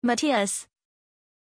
Pronunciación de Mattias
pronunciation-mattias-zh.mp3